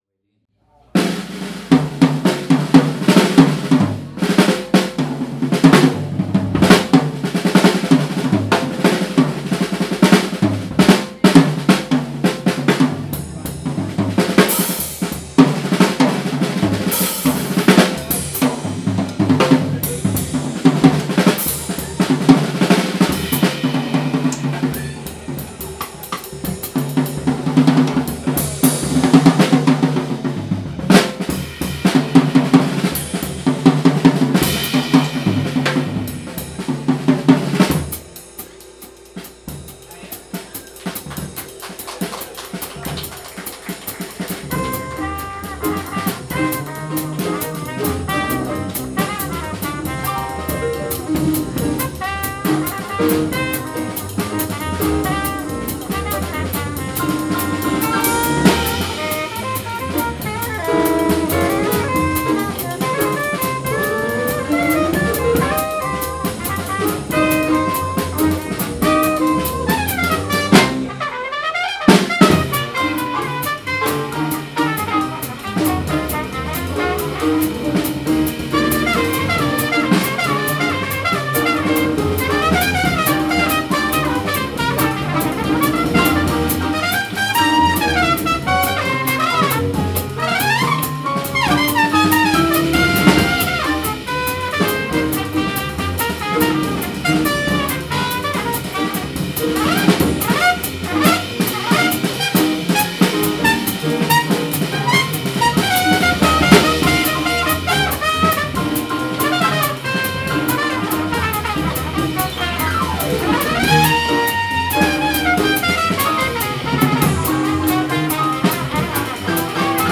we played a burning version of this jazz standard
starting with an intense drum solo